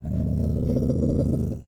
Minecraft Version Minecraft Version latest Latest Release | Latest Snapshot latest / assets / minecraft / sounds / mob / wolf / angry / growl1.ogg Compare With Compare With Latest Release | Latest Snapshot
growl1.ogg